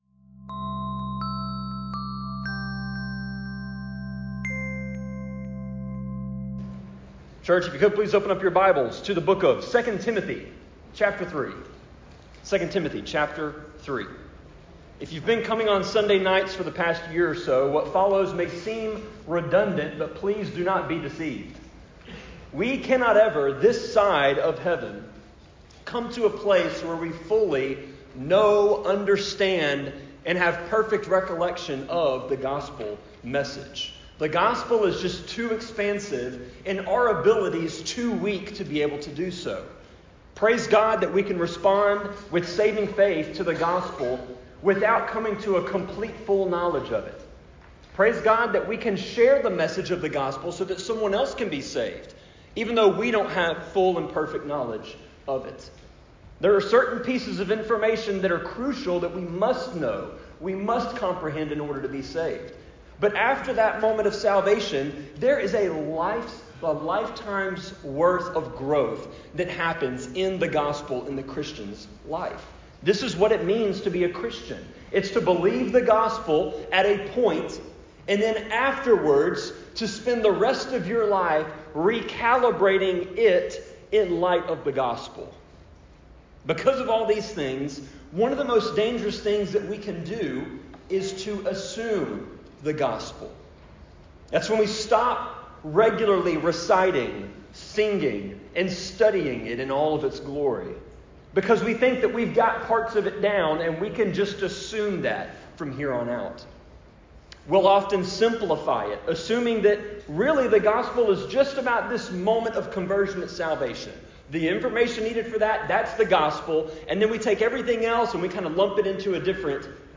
Sermon-25.9.28-CD.mp3